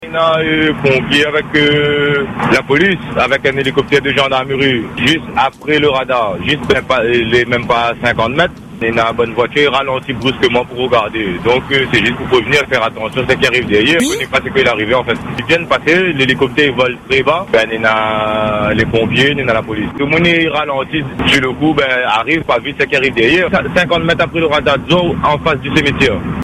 Réécoutez l’intervention de l’auditeur de Free Dom :